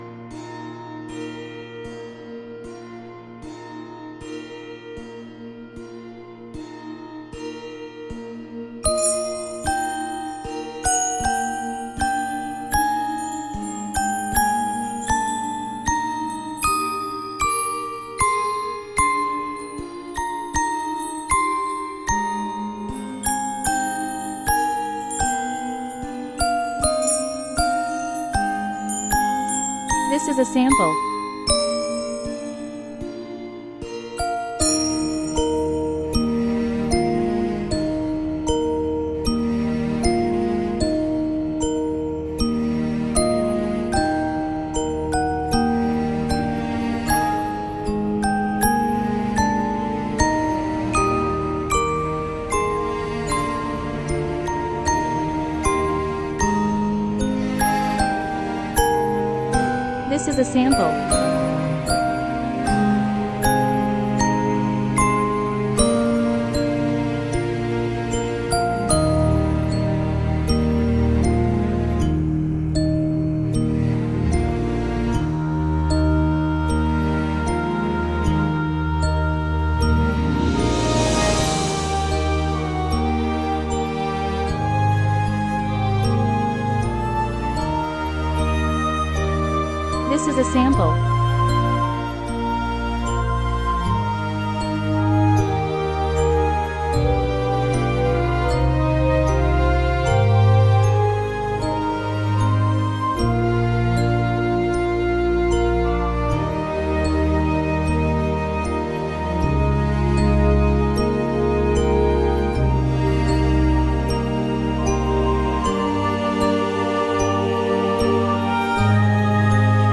reverent carol